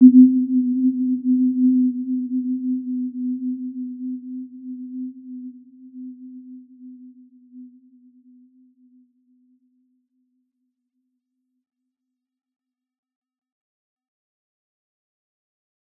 Warm-Bounce-C4-mf.wav